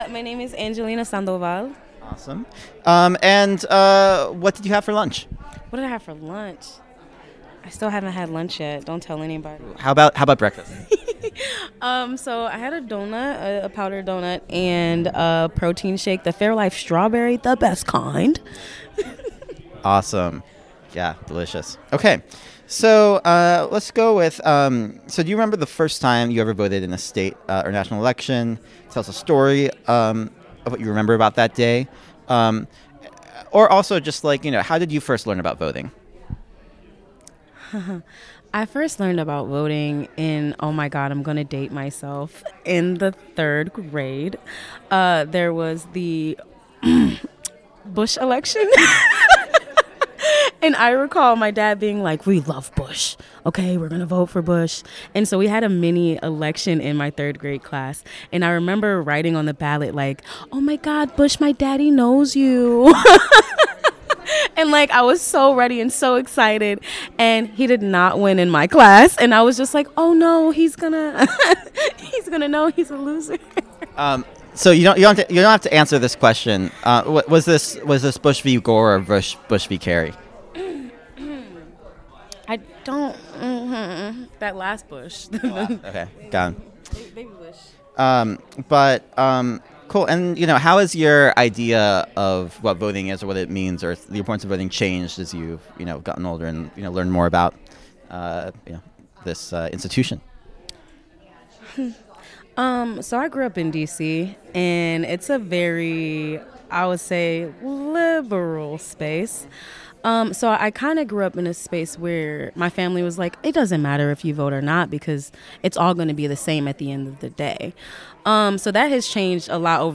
Milwaukee Central Library